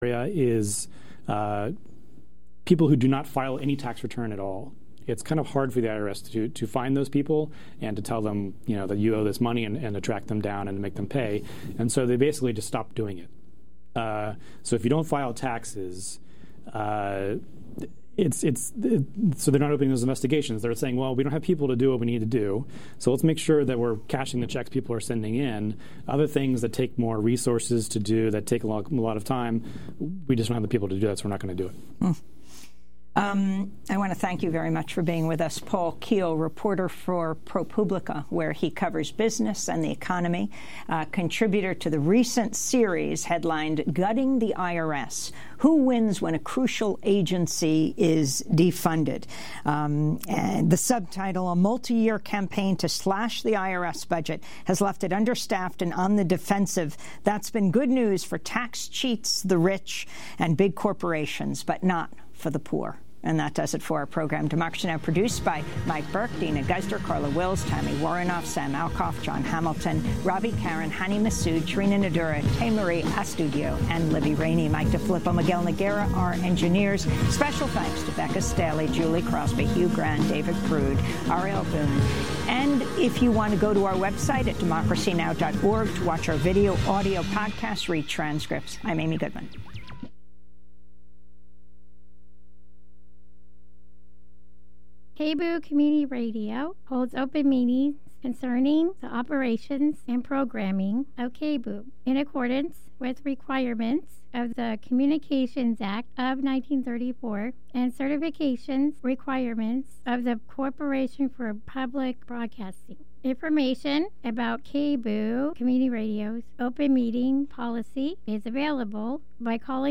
Evening News on 01/14/19